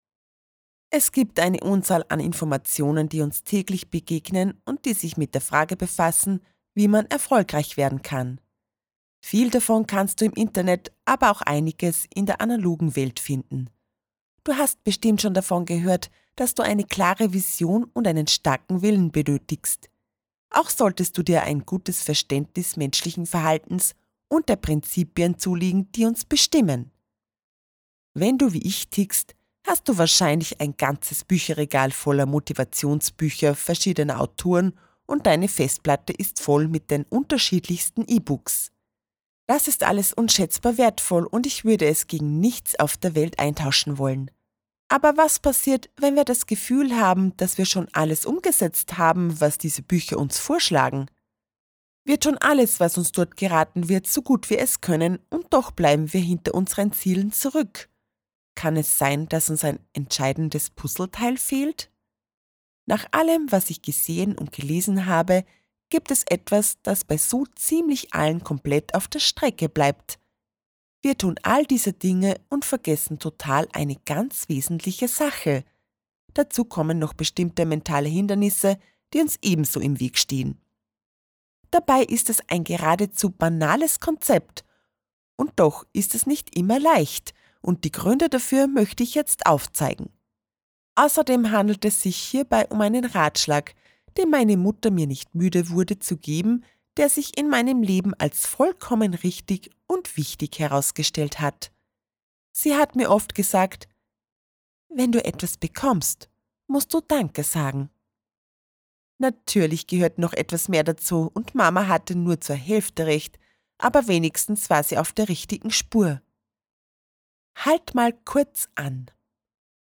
Hörbücher
Ratgeber
In meinem Studio arbeite ich ausschließlich mit hochwertigem Equipment und garantiere Dir somit hohe Qualität und top Service.